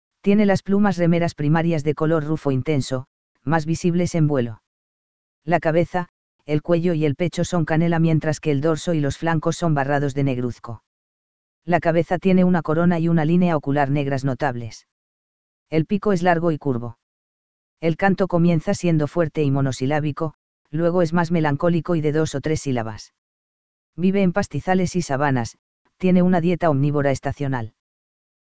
El canto comienza siendo fuerte y “monosilábico”, luego es más melancólico y de dos o tres “sílabas”.